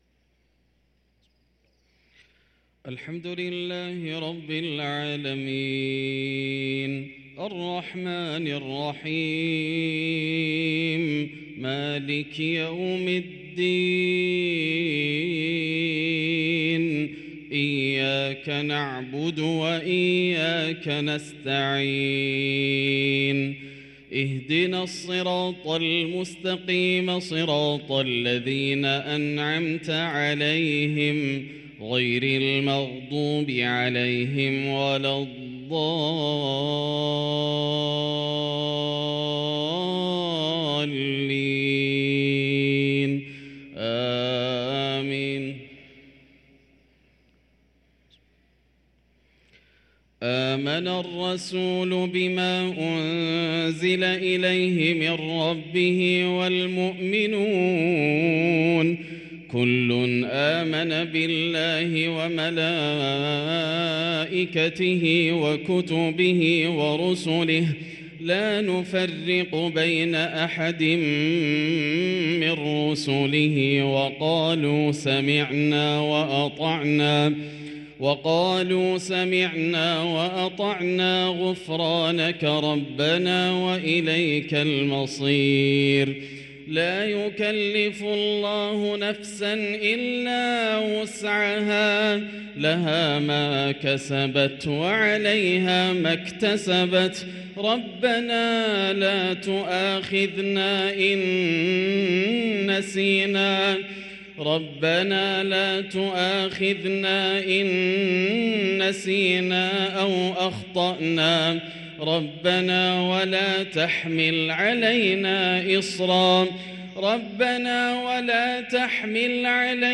صلاة المغرب للقارئ ياسر الدوسري 19 رجب 1444 هـ
تِلَاوَات الْحَرَمَيْن .